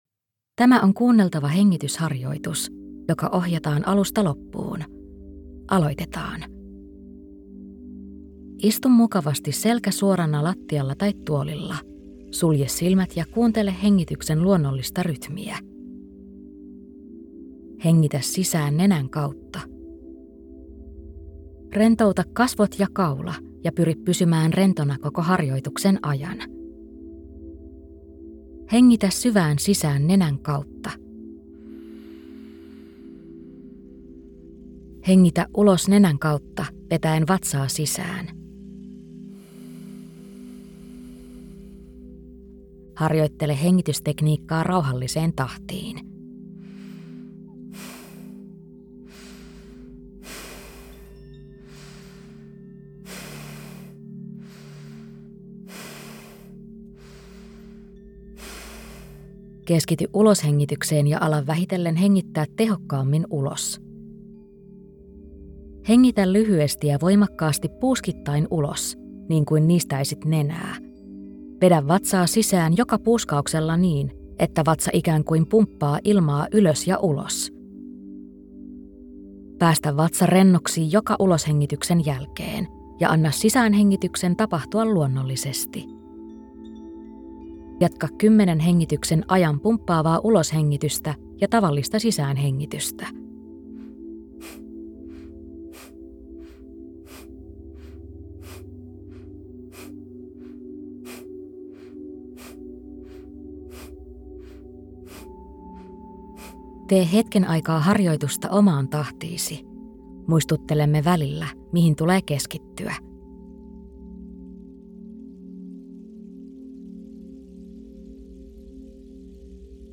Tulihengitys – ohjattu kuunneltava hengitysharjoitus
• Äänite alkaa johdannolla hengitysharjoituksesta.
• Lopuksi on 2 minuuttia aikaa tuntea harjoituksen vaikutus.
Hengitysharjoite koostuu lyhyistä, räjähtävistä uloshengityksistä, jotka työntävät ilmaa ulos keuhkoista, ja passiivisesta sisäänhengityksestä.